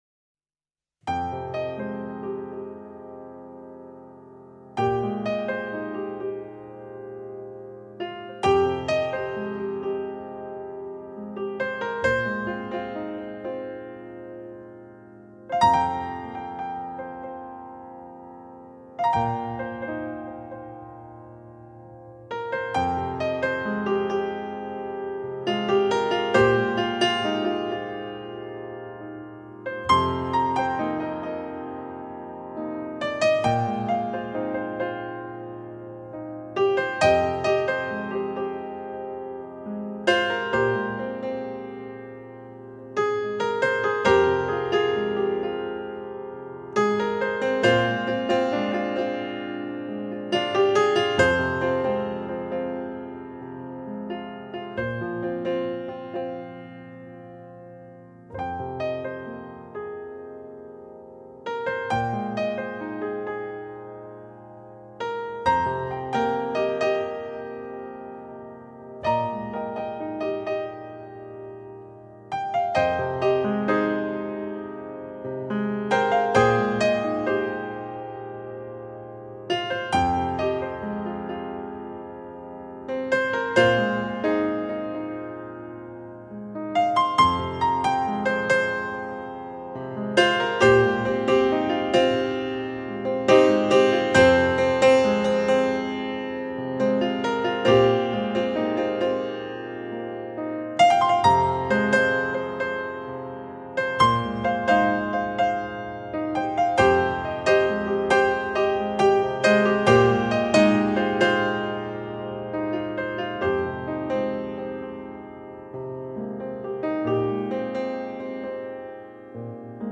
本CD音乐之音源采用当今世界DVD音源制作最高标准，令声音光芒四射，再现完美终级之声。
洗尽铅华之后的素净琴音，在霎那之间就牵动你心中回忆，那甘甜中略带酸涩的感觉便又在心头泛起……